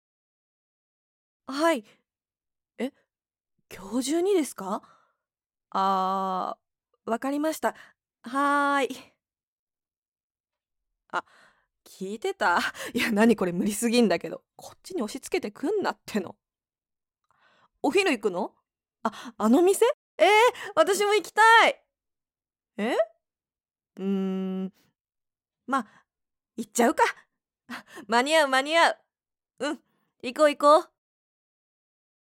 ボイスサンプル
OLの日常